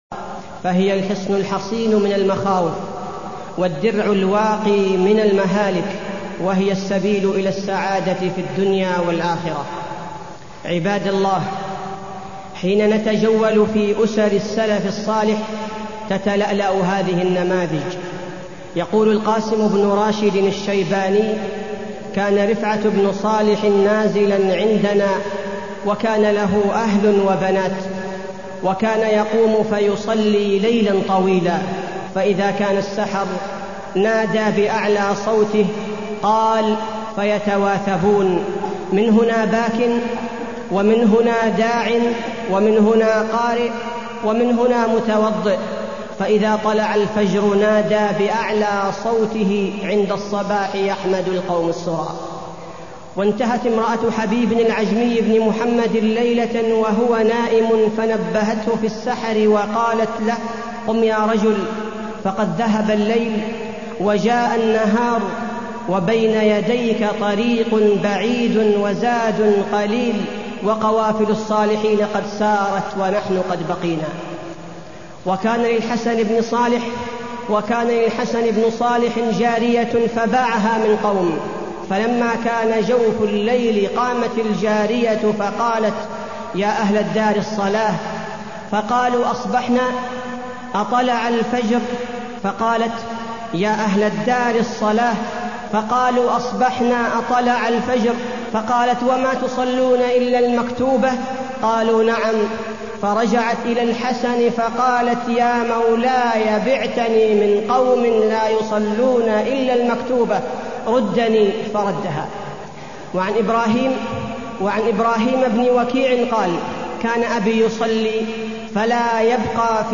تاريخ النشر ١٢ ربيع الثاني ١٤٢١ هـ المكان: المسجد النبوي الشيخ: فضيلة الشيخ عبدالباري الثبيتي فضيلة الشيخ عبدالباري الثبيتي الأسرة المسلمة